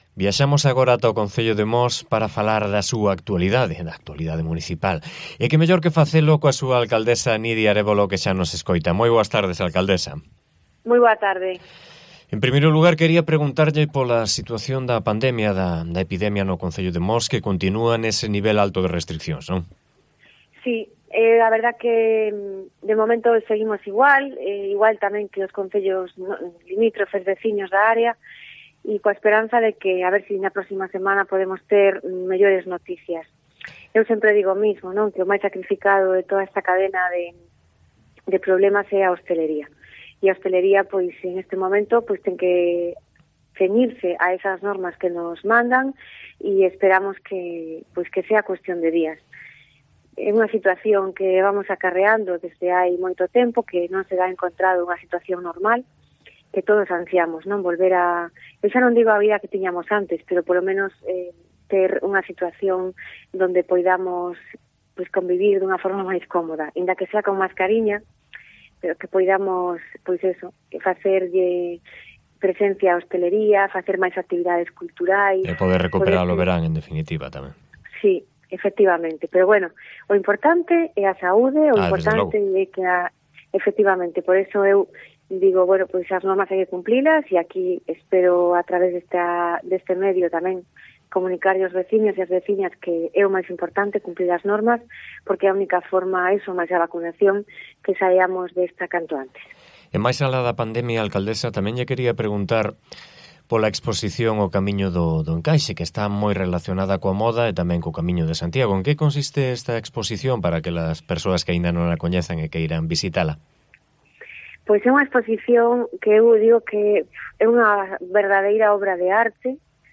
Entrevista a Nidia Arévalo, alcaldesa de Mos